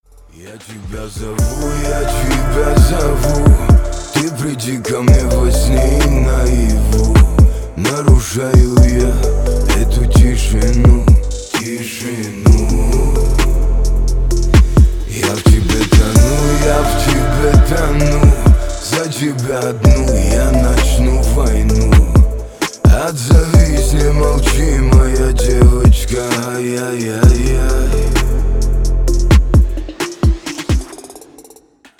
• Качество: 320, Stereo
мужской голос
русский рэп
спокойные
романтичные